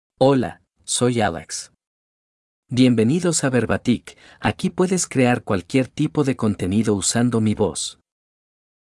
MaleSpanish (Peru)
Alex — Male Spanish AI voice
Alex is a male AI voice for Spanish (Peru).
Voice sample
Alex delivers clear pronunciation with authentic Peru Spanish intonation, making your content sound professionally produced.